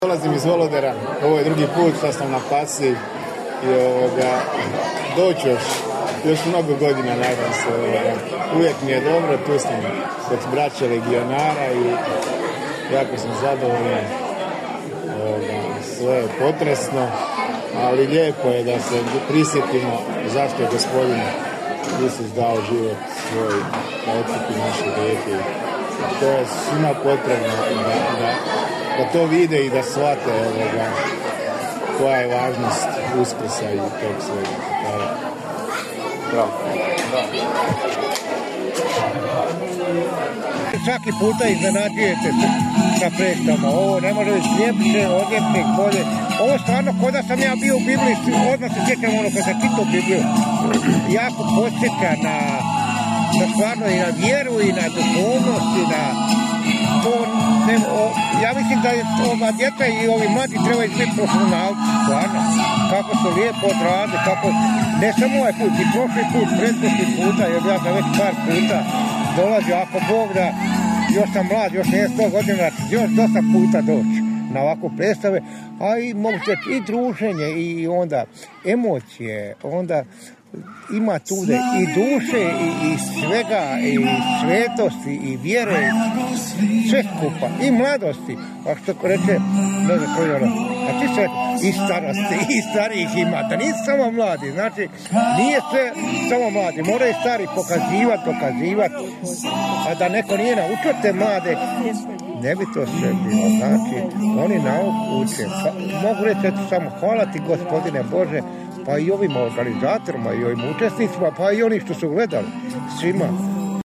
Nakon izvedbe smo razgovarali i s publikom.